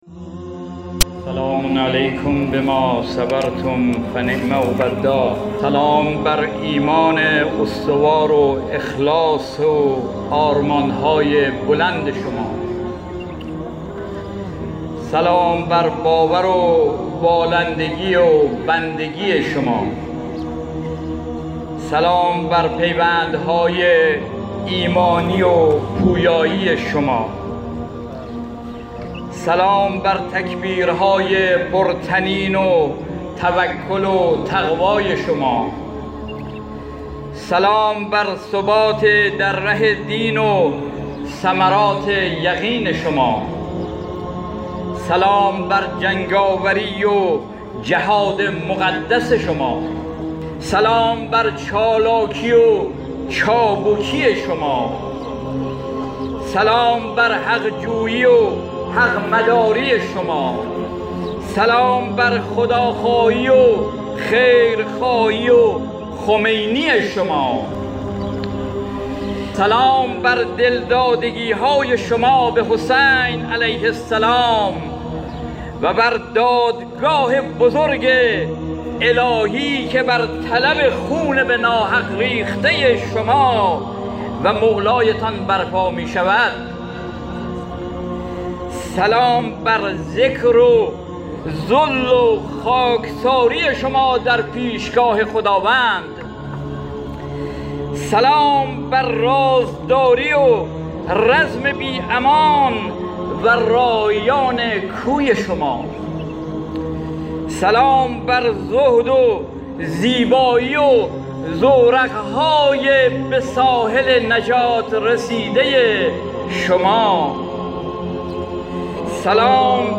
گروه جهاد و حماسه ــ مسئول کمیته جستجوی مفقودین ستادکل نیروهای مسلح سلام‌نامه‌ای را برای شهدای تازه تفحص شده قرائت کرده است.
سلام سردار باقرزاده به شهدا + صوتبه گزارش خبرنگار ایکنا، سردار باقرزاده، مسئول کمیته جستجوی مفقودین ستاد کل نیروهای مسلح به مناسبت ویژه‌برنامه استقبال از شهدای تازه تفحص شده در معراج شهدا، متنی با عنوان سلام‌نامه شهیدان را بازخوانی کرده است.